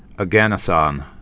(ə-gănə-sŏn, ôgə-nĕsŏn, -ən)